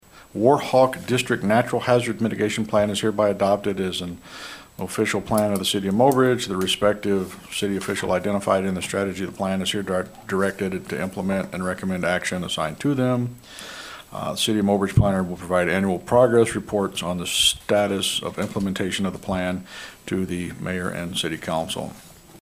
Also at Wednesday’s council meeting:
Council passed a motion to approve a resolution adopting a hazard mitigation plan.  Mayor Gene Cox read the resolution.